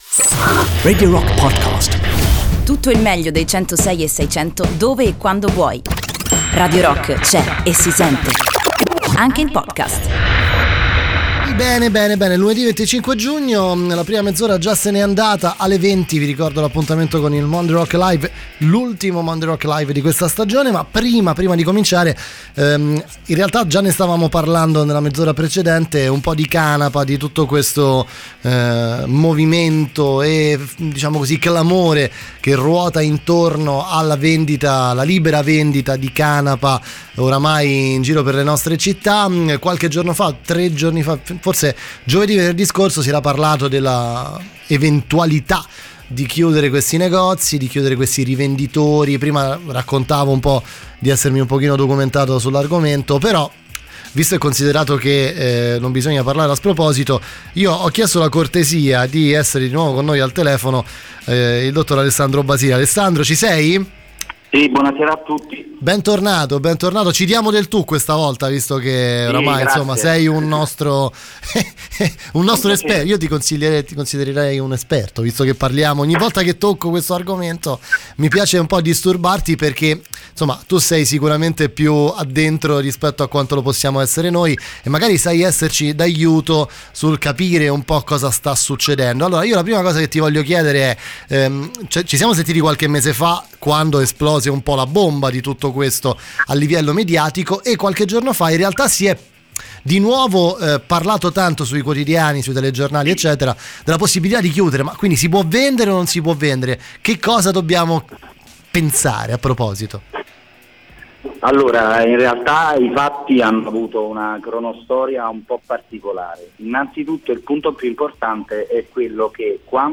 Intervista
al telefono negli studi di Radio Rock